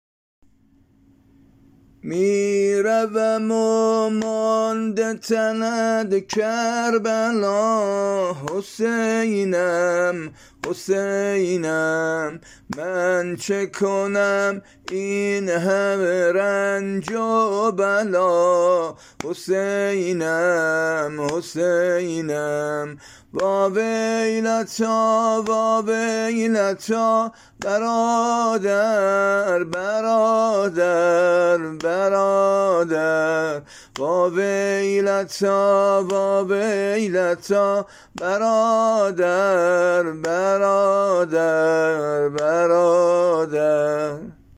نوحه وزمزمه احوال حضرت زینب